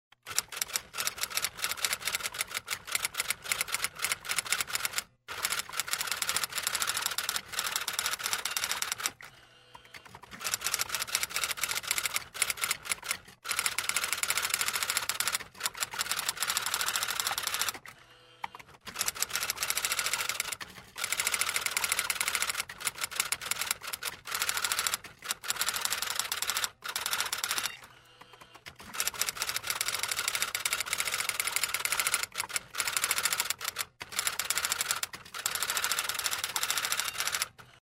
Звуки программирования
Звук электрической пишущей машинки